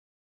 normal-slidertick.wav